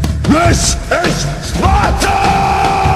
Worms speechbanks
Watchthis.wav